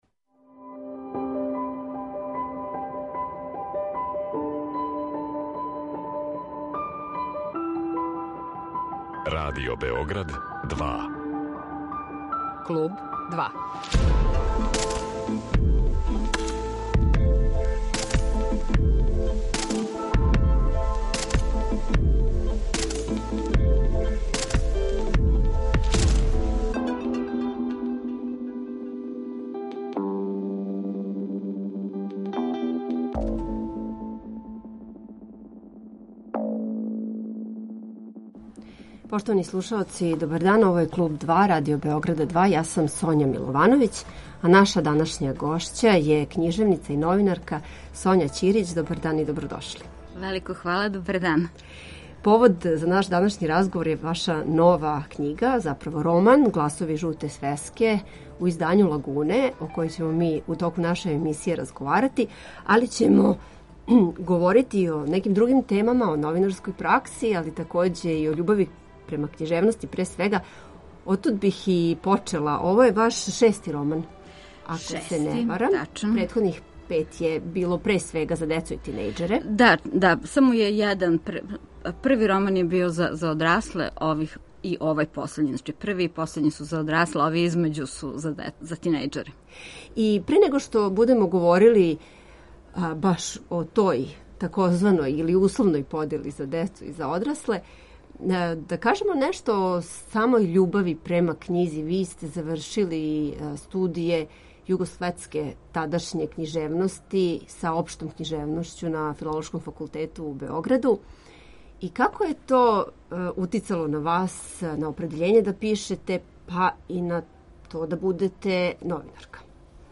Радио Београд 2